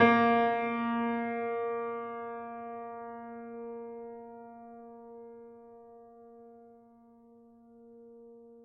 Steinway_Grand